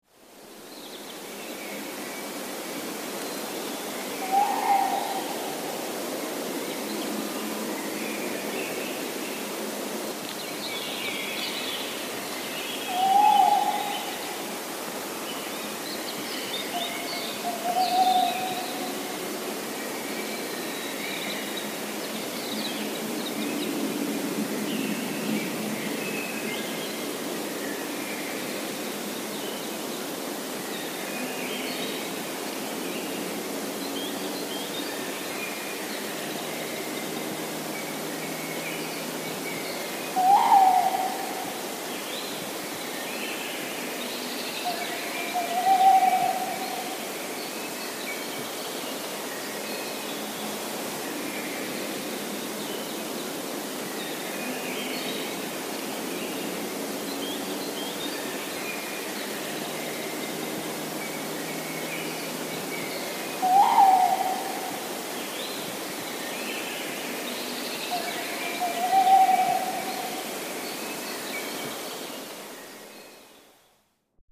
Tę pleszkę nagrałam w Laskach 22 kwietnia 2018 roku. Na nagraniu w oddali słychać kwiczoła, sójkę i kowalika. Pleszce towarzyszy głos wabiący innej pleszki.
Głos godowy samca puszczyka nagrałam 11 kwietnia 2016 roku. Na nagraniu gdzieś daleko śpiewa kos. Nagranie zostało zrobione bardzo wcześnie rano.
Samicę puszczyka nagrałam również w Laskach 25 lutego wieczorem w 2017 roku. Słychać tylko gdzieś daleko szczekające psy.
Jednak później dowiedziałam się, że nagrane pohukiwanie należy do samicy, ale jest ono mniej donośne niż u samca i trochę schrypnięte.